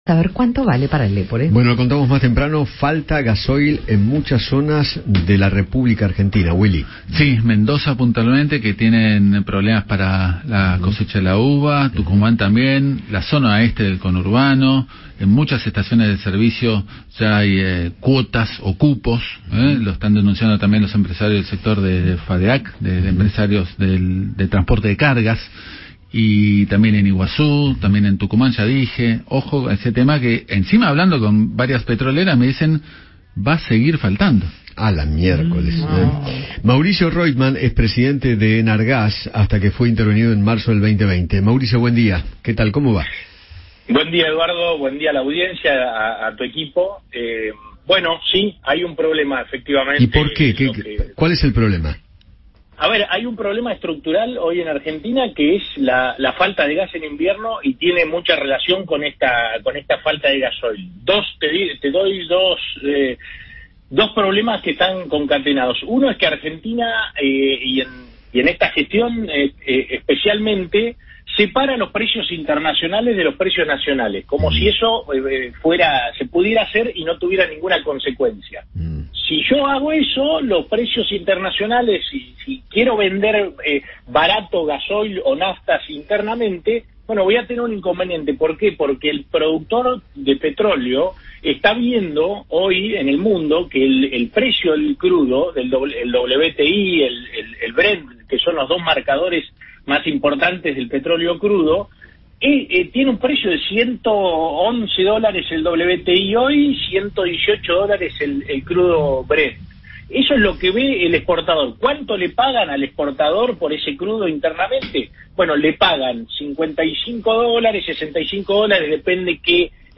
Mauricio Roitman, ex presidente de ENARGAS, conversó con Eduardo Feinmann sobre la escasez de gasoil en varias estaciones de servicio del país.